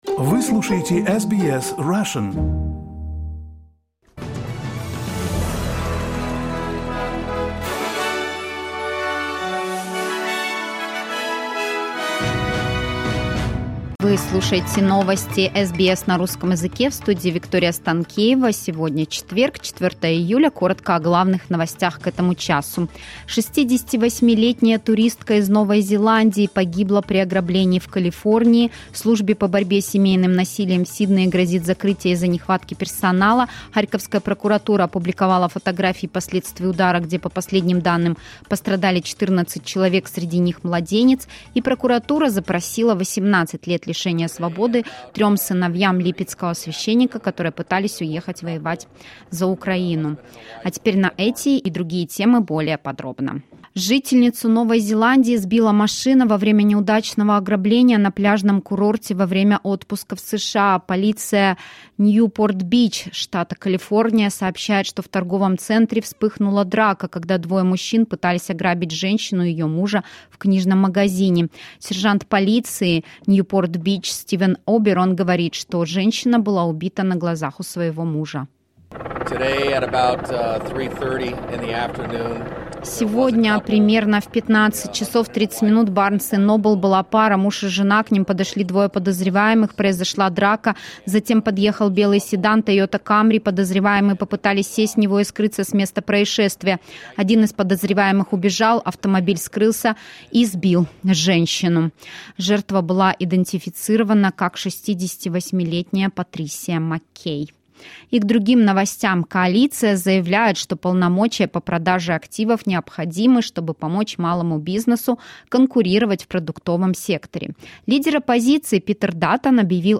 Latest news headlines in Australia from SBS Russian